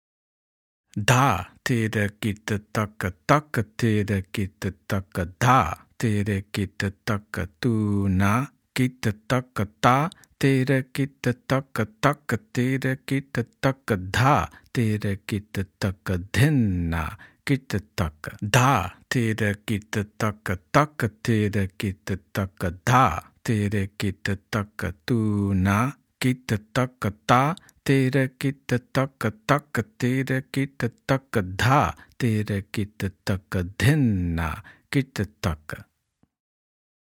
Slow Speed – Spoken